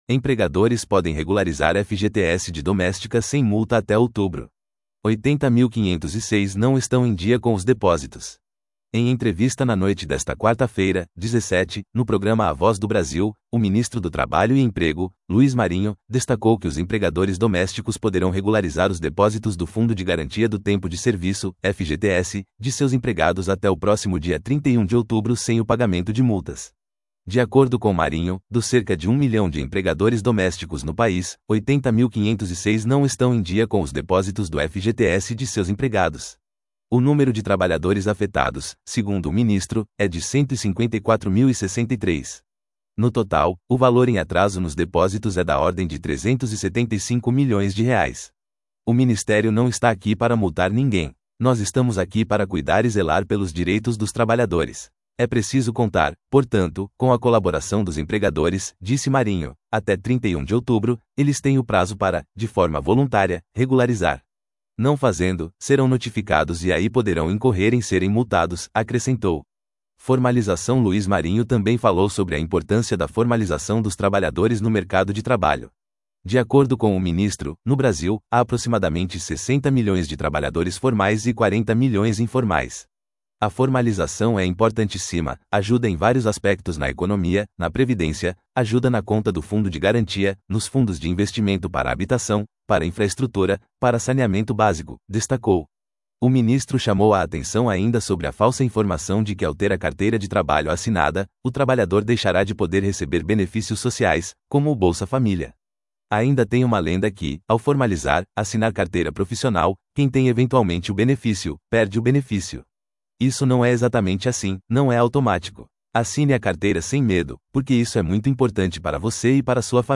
Em entrevista na noite desta quarta-feira (17), no programa A Voz do Brasil, o ministro do Trabalho e Emprego, Luiz Marinho, destacou que os empregadores domésticos poderão regularizar os depósitos do Fundo de Garantia do Tempo de Serviço (FGTS) de seus empregados até o próximo dia 31 de outubro sem o pagamento de multas.